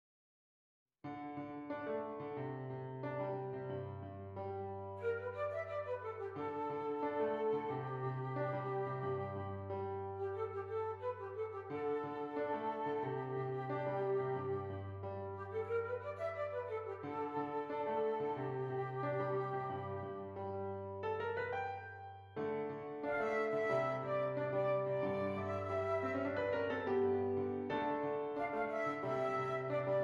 Flute Solo with Piano Accompaniment
Does Not Contain Lyrics
G Major
Moderate